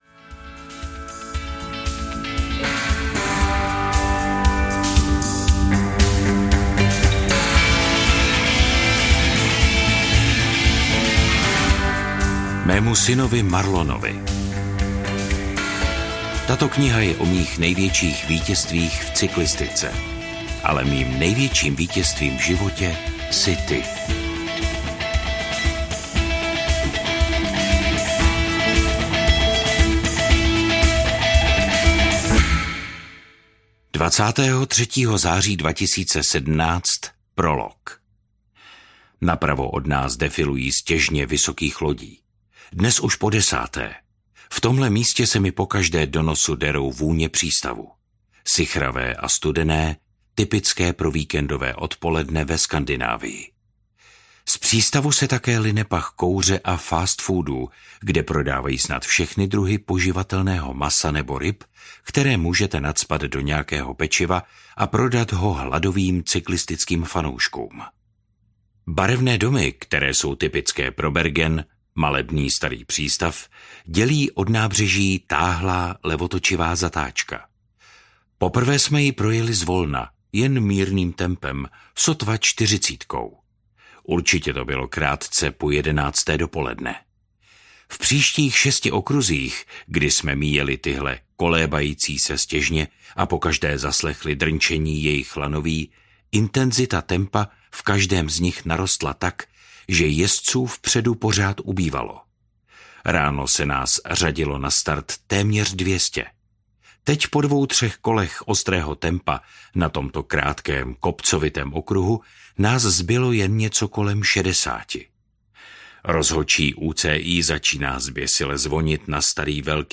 Můj svět audiokniha
Ukázka z knihy
• InterpretBohdan Tůma